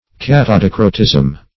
Search Result for " catadicrotism" : The Collaborative International Dictionary of English v.0.48: Catadicrotism \Cat`a*di"cro*tism\, n. [Cata- + dicrotism.]
catadicrotism.mp3